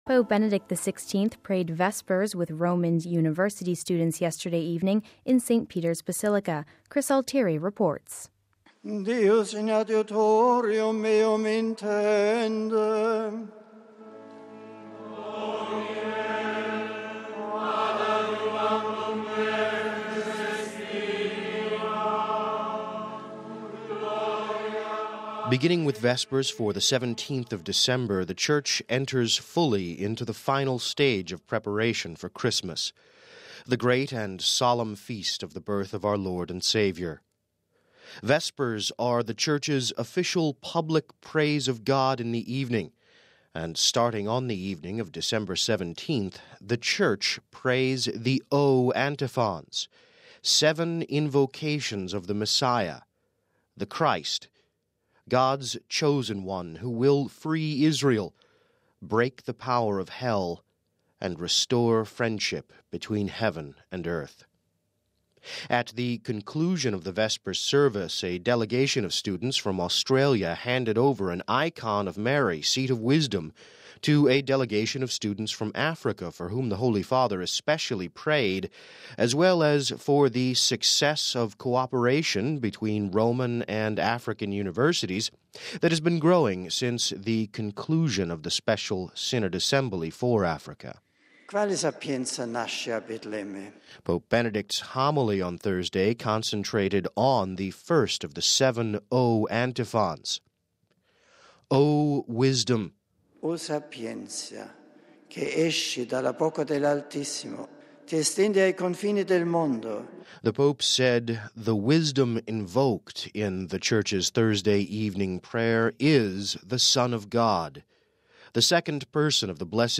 (18 Dec 09 - RV) Pope Benedict XVI prayed Vespers with Roman university students yesterday evening in St. Peter’s Basilica: RealAudio